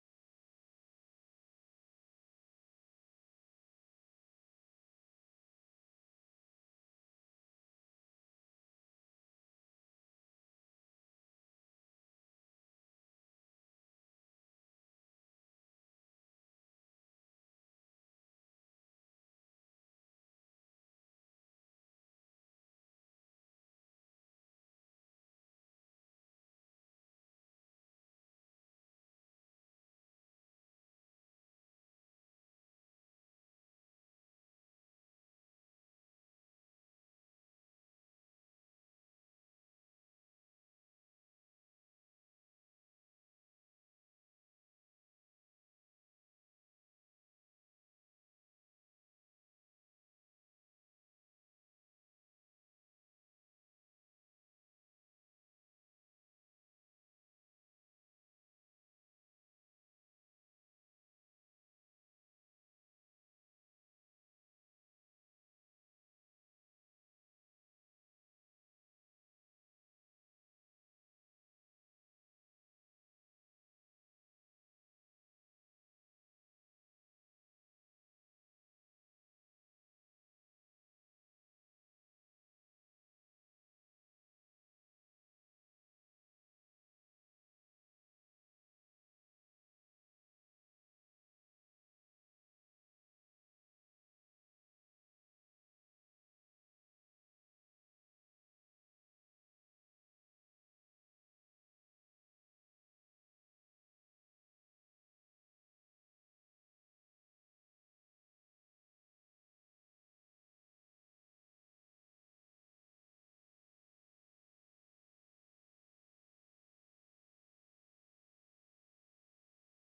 Tune in for a friendly yet insightful discussion filled with humor, relatable stories, and actionable advice to ensure your marriage flourishes instead of flounders.